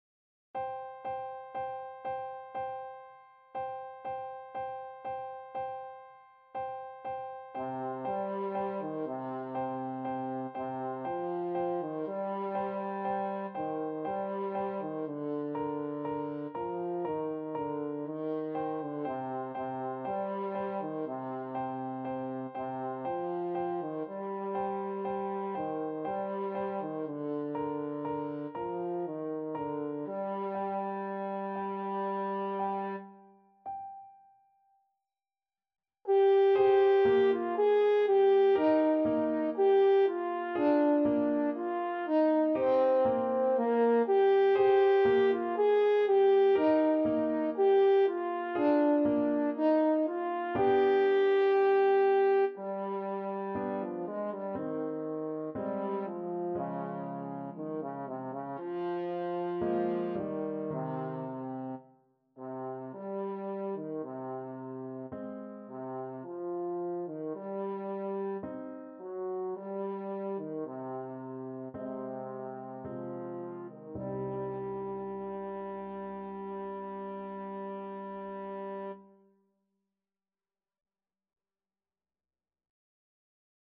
3/4 (View more 3/4 Music)
Andante sostenuto =60
Classical (View more Classical French Horn Music)